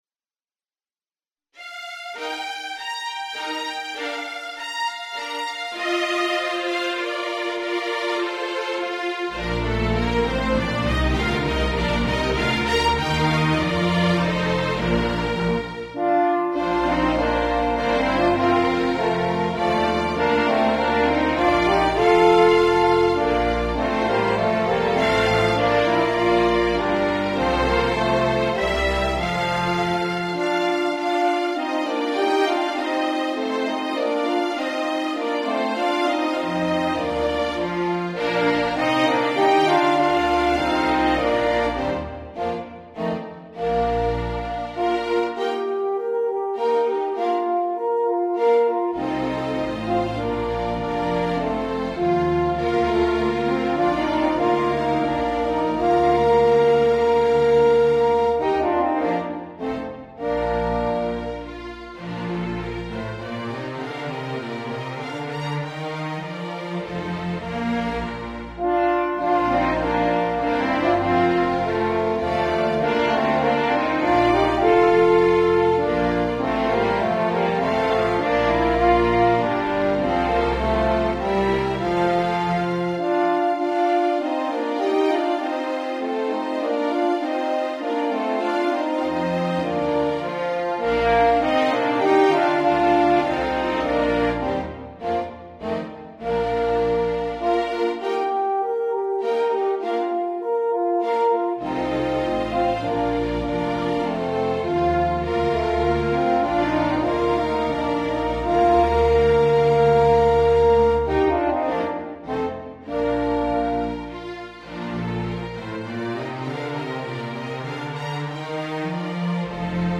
The MP3 was recorded with NotePerformer.